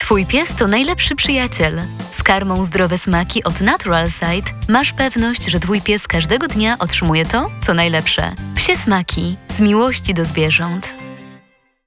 Udzielam głosu do nagrań lektorskich – audiobooki, teksty medytacyjne, reklamy, filmy instruktażowe, dokumentalne i fabularne.